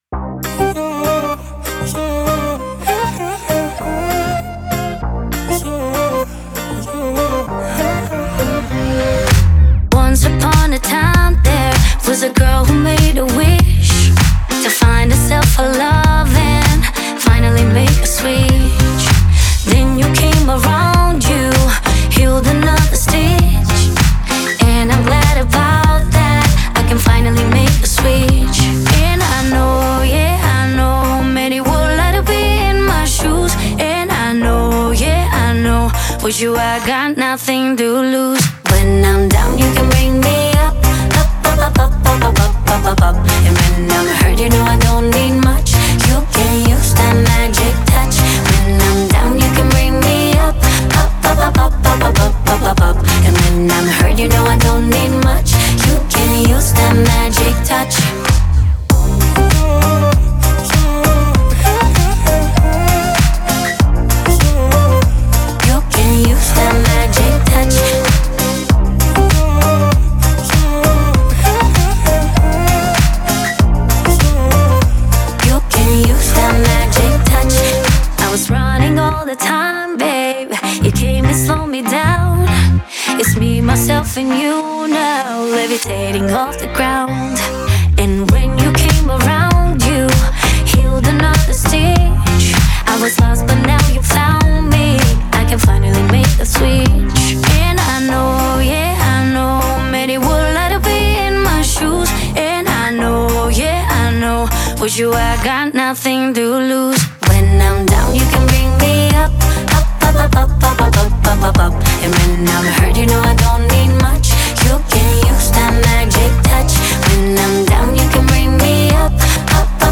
Смешная такая песенка, но симпатичная))